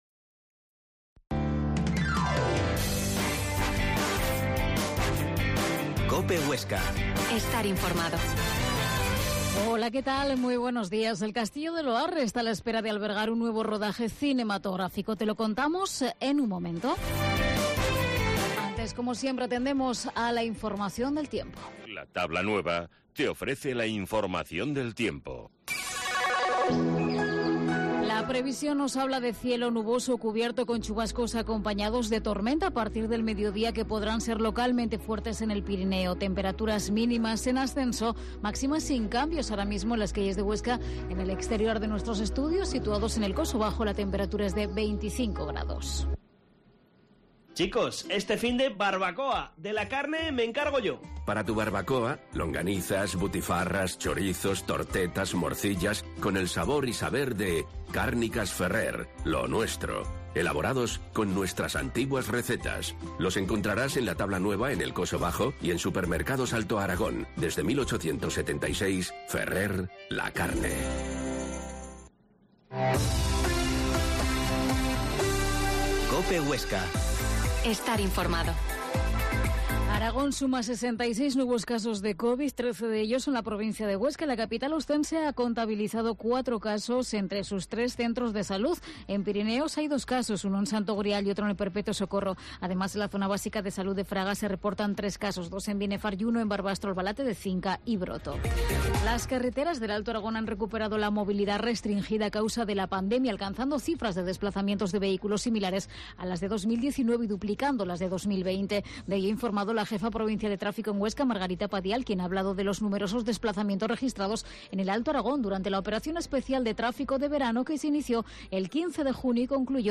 Herrera en Cope Huesca 12,50h. Entrevista al alcalde de Loarre